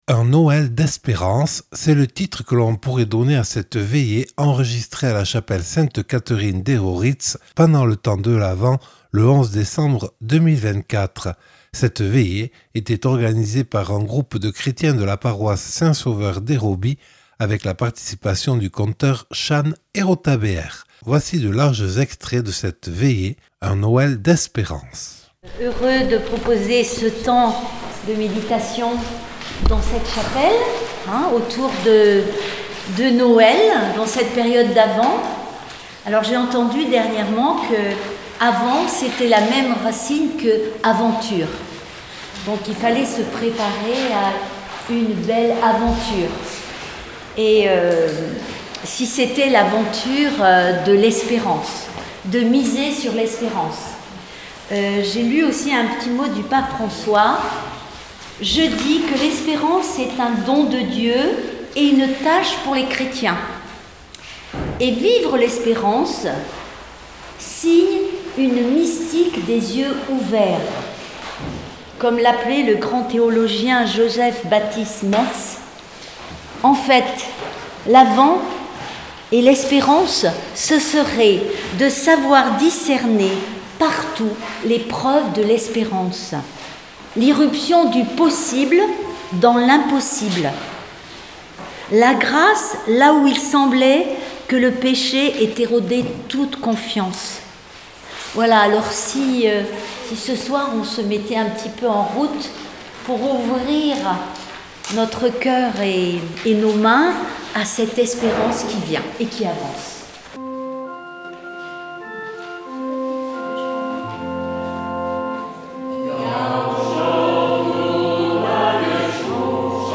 Veillée enregistrée le 11/12/2024 à la chapelle Sainte-Catherine d’Hérauritz